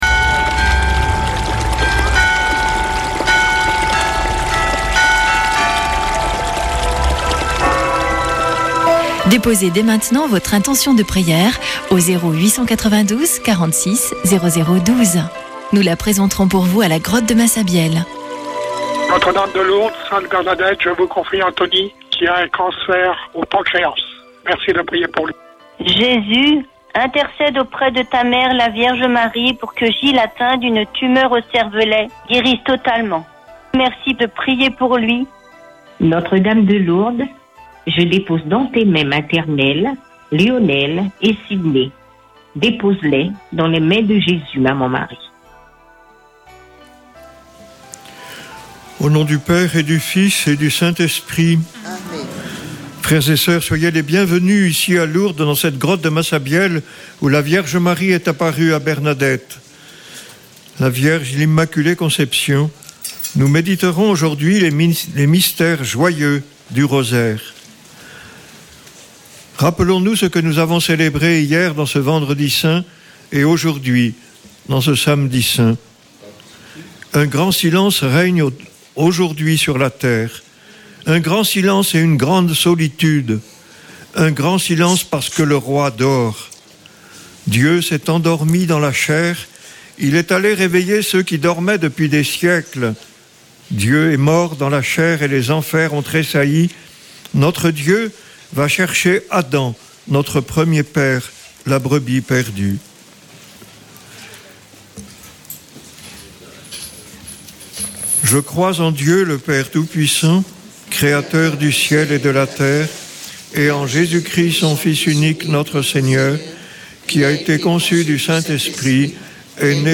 Chapelet de Lourdes du 04 avr.
Une émission présentée par Chapelains de Lourdes